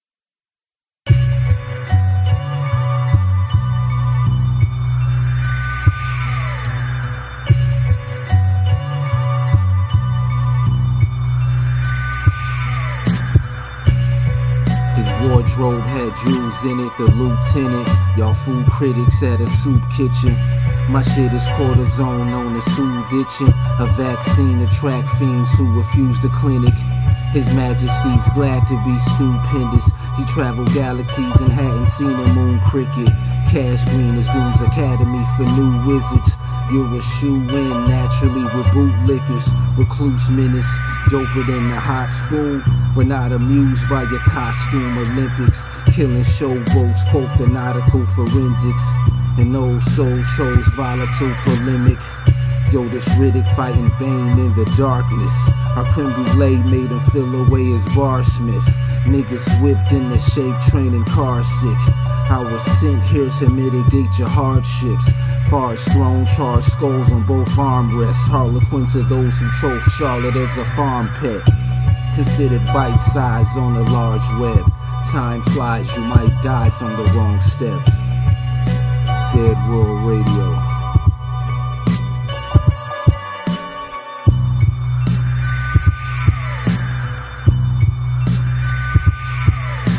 A freestyle loosie